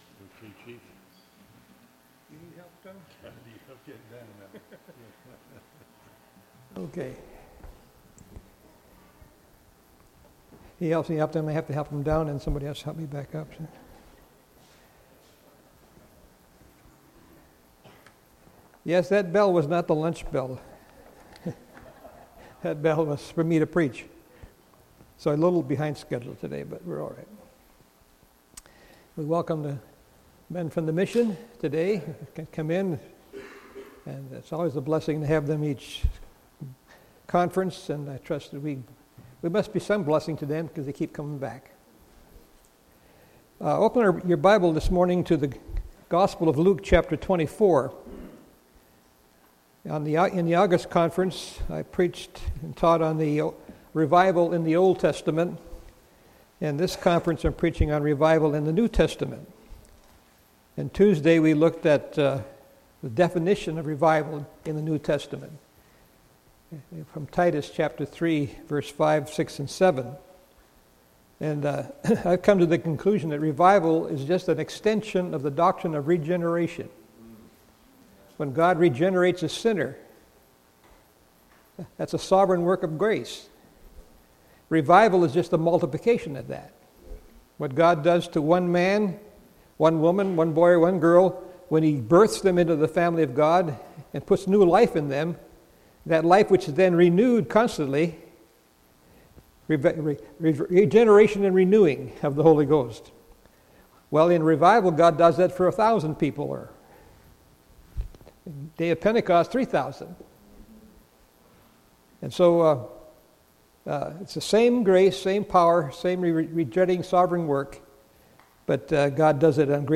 Session: Morning Session